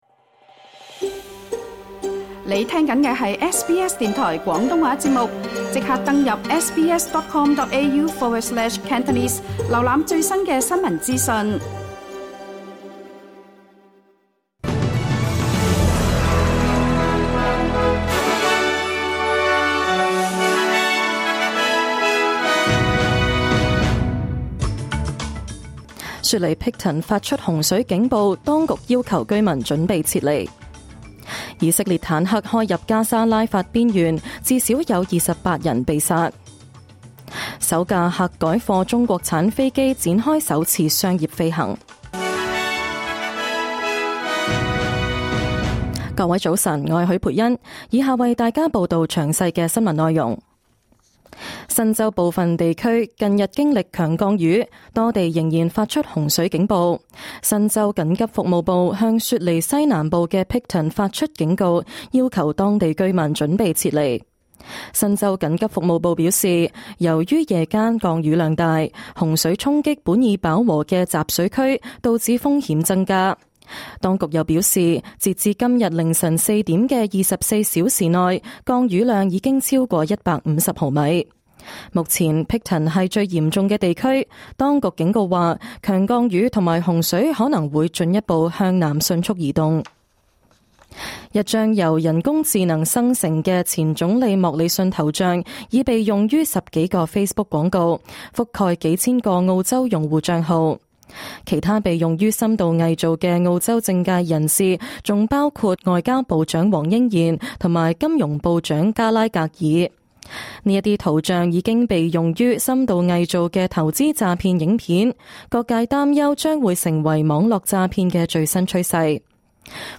2024年6月8日SBS廣東話節目詳盡早晨新聞報道。